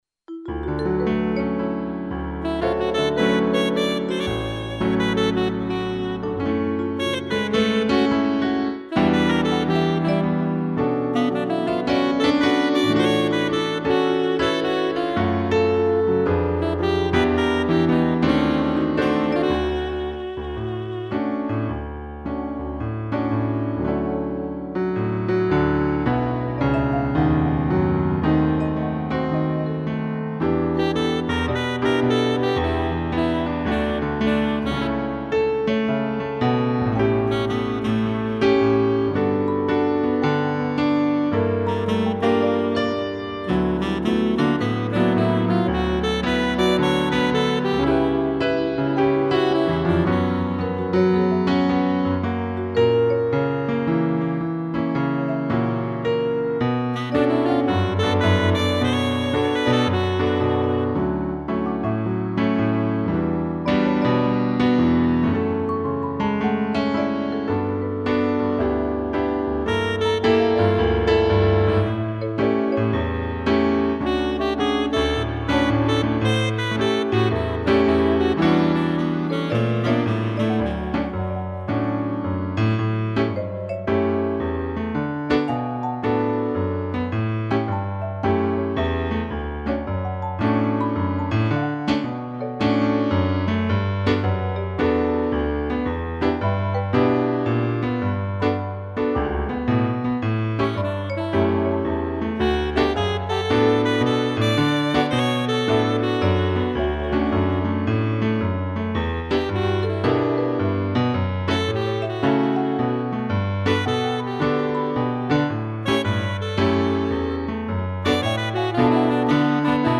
2 pianos e trombone
(instrumental)